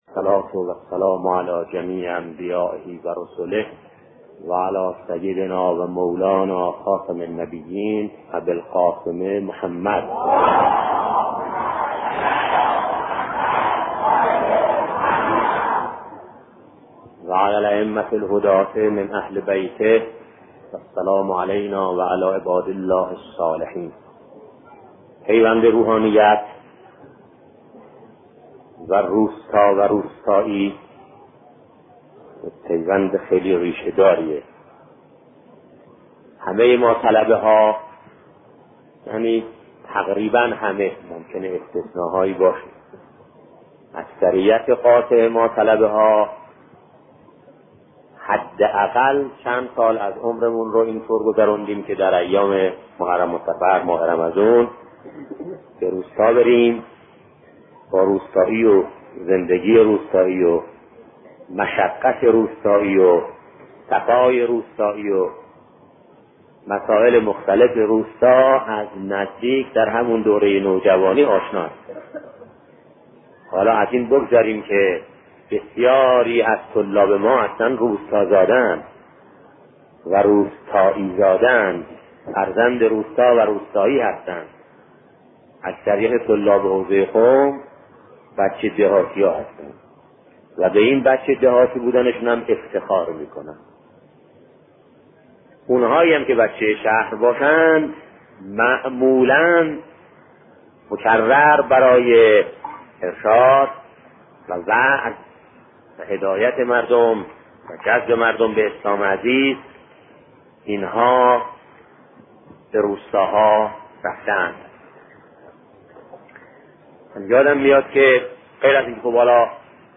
سخن شهید بهشتی در خصوص پیوند روحانیت و زندگی روستاییان